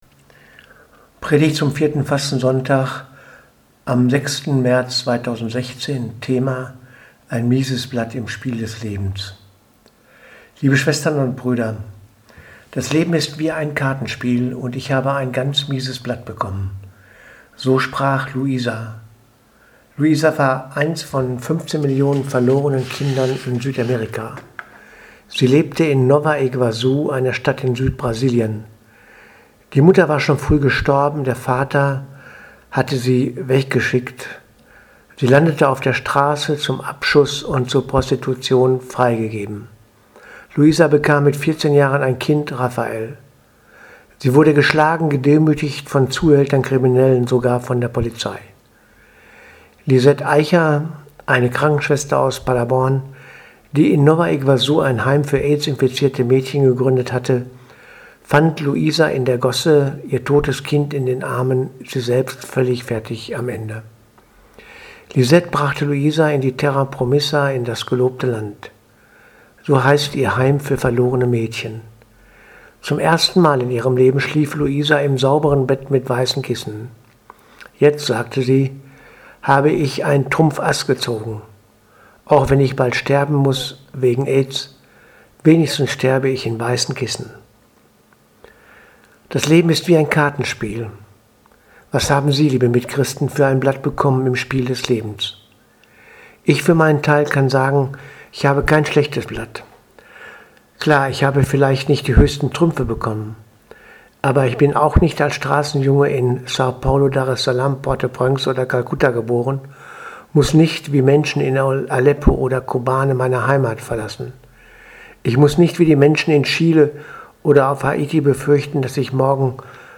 Predigt zum 24. Sonntag im Jahreskreis 13. Sept. 2015 Thema: Wer bin ich? Evangelium: Mk 8, 27-35